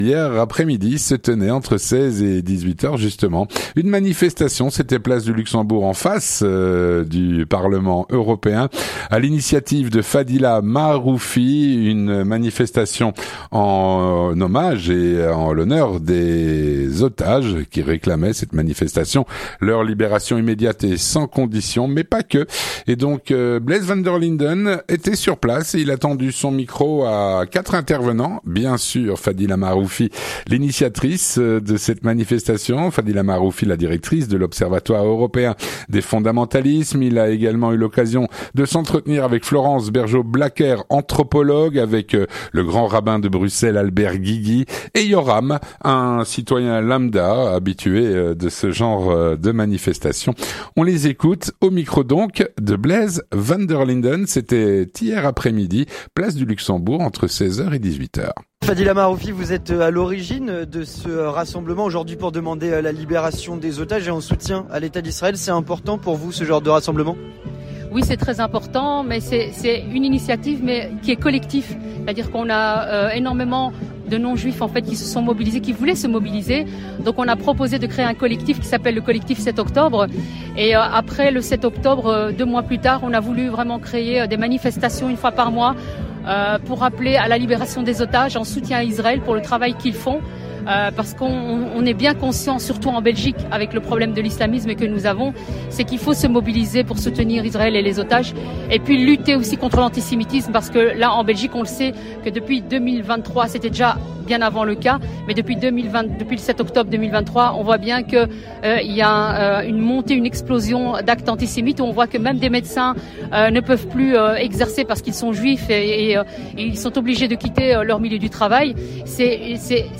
Une manifestation a eu lieu place du Luxembourg à Bruxelles pour exiger la libération des otages.
anthropologue
- Le Grand Rabbin de Bruxelles, Albert Guigui,